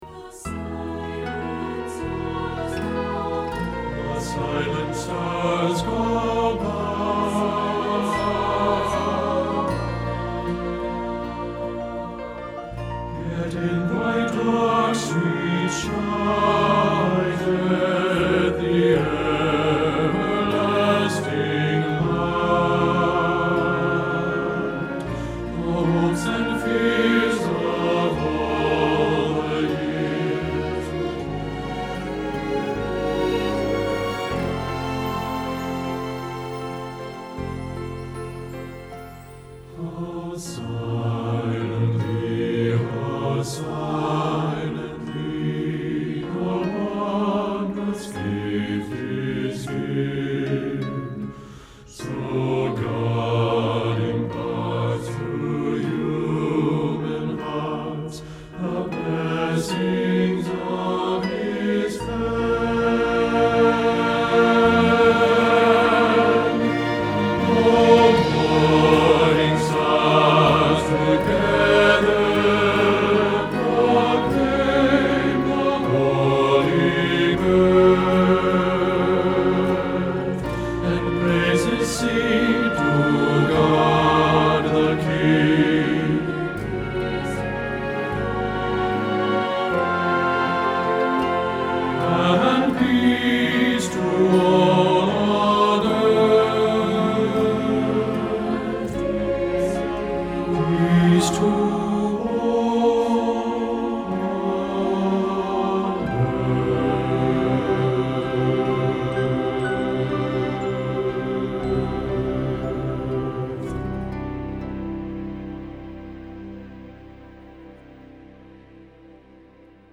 O Little Town of Bethlehem – Bass – Hilltop Choir
O-Little-Town-of-Bethelehem-Bass-Edit.mp3